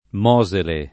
[ m 0@ ele ]